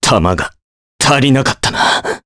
Crow-Vox_Dead_jp.wav